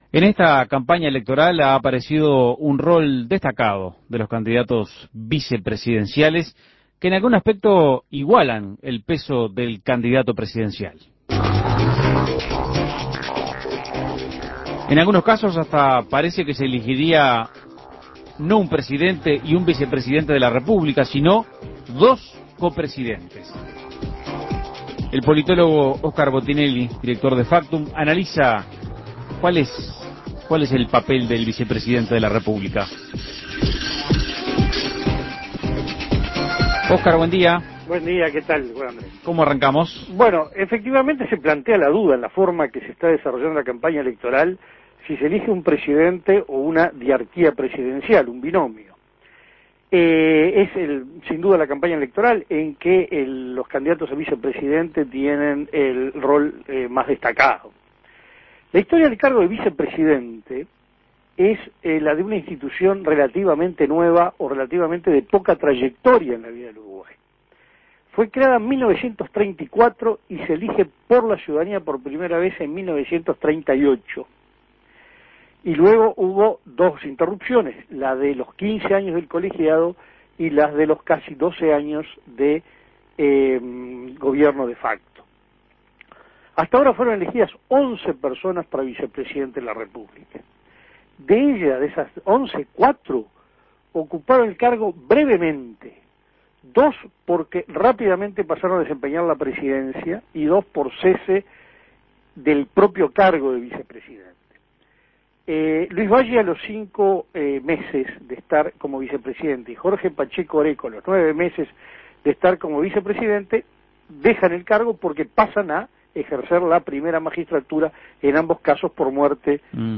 análisis político